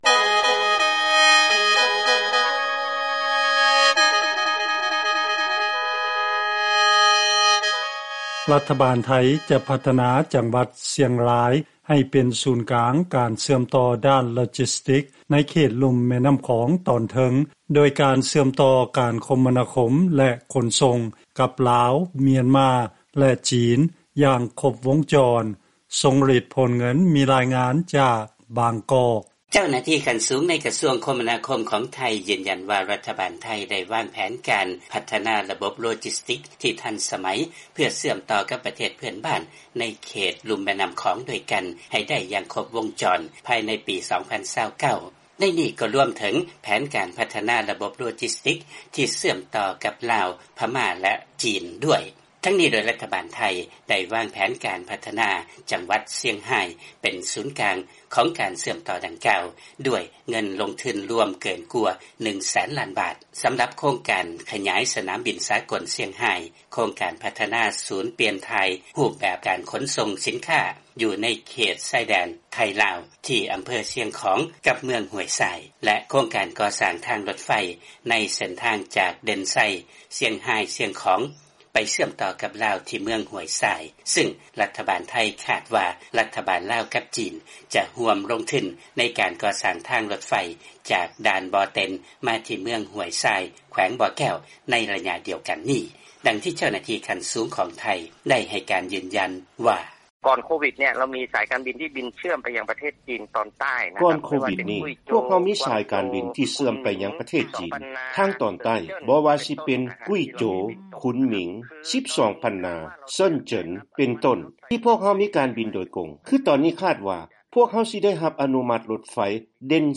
ລາຍງານກ່ຽວກັບ ລັດຖະບານໄທ ຈະພັດທະນາຈັງຫວັດຊຽງຮາຍໃຫ້ເປັນສູນກາງການຂົນສົ່ງ ໃນຂົງເຂດແມ່ນໍ້າຂອງຕອນເໜືອ